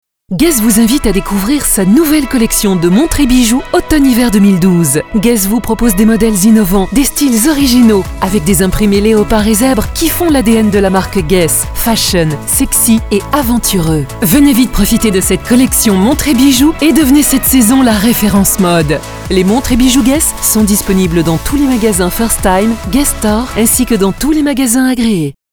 Pymprod réalise pour vous des spots publicitaires avec nos voix off masculines et féminines.